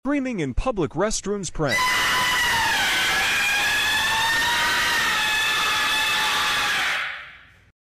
Screaming In Public Restrooms | Sound Effects Free Download
screaming In public restrooms | sound effects free download